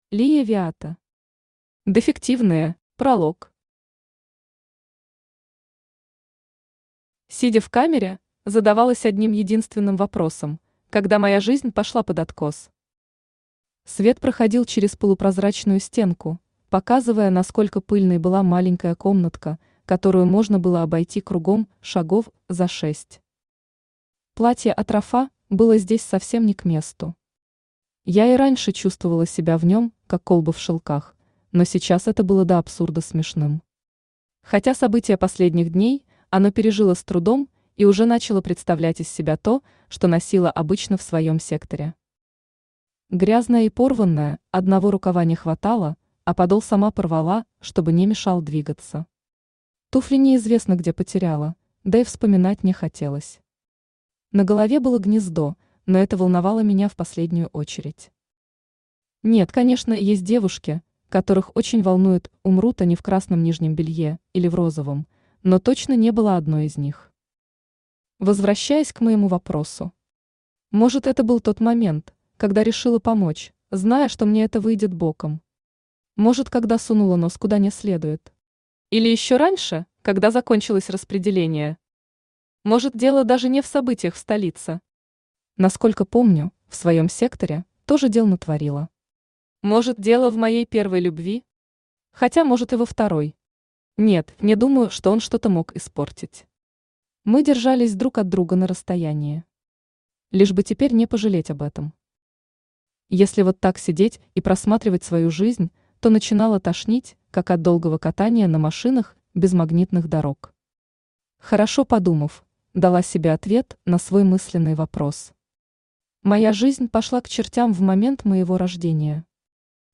Аудиокнига Дефективные | Библиотека аудиокниг
Aудиокнига Дефективные Автор Лия Виата Читает аудиокнигу Авточтец ЛитРес.